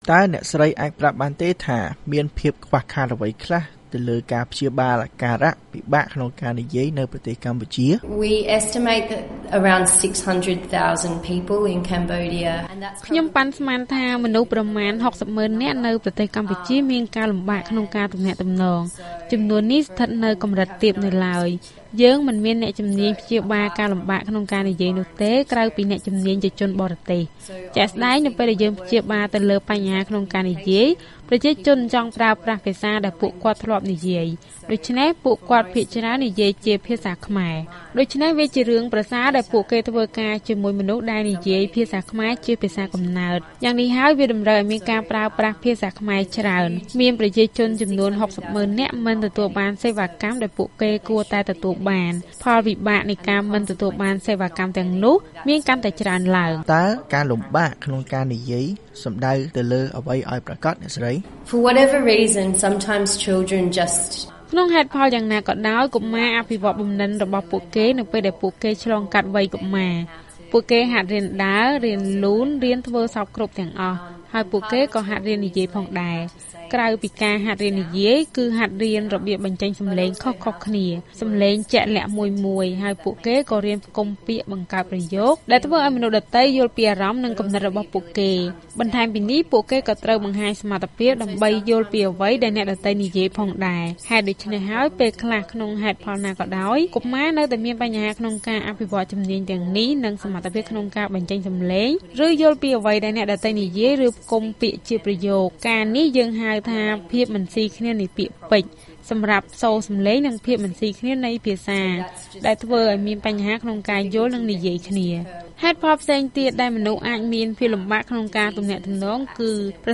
បទសម្ភាសន៍ VOA៖ អ្នកជំនាញប៉ាន់ប្រមាណថា ពលរដ្ឋខ្មែរ៦សែននាក់មានអាការៈពិបាកក្នុងការនិយាយស្ដី